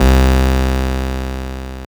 Juno Saw C1.wav